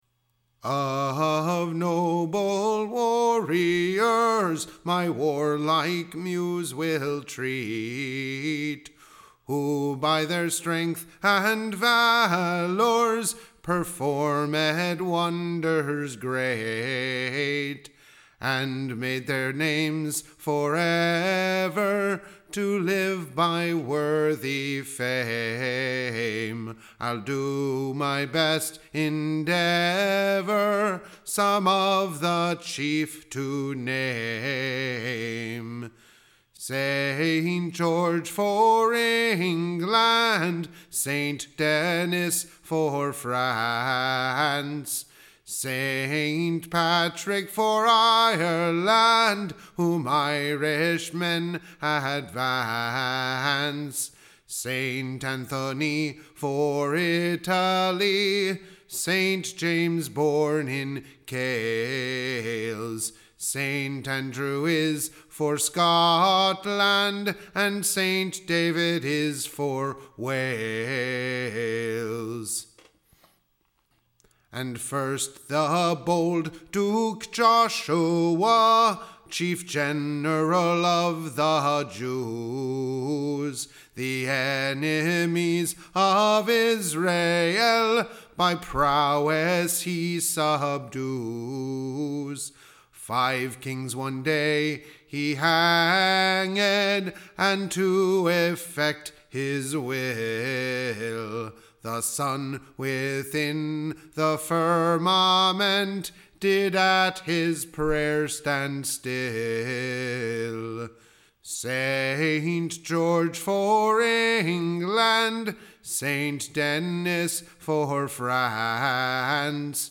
Recording Information Ballad Title A brave warlike Song. / Containing a briefe rehearsall of the deeds of Chivalry, perfor- / med by the Nine VVorthies of the world, the seaven Champions of / Christendome, with many other remarkable Warriours.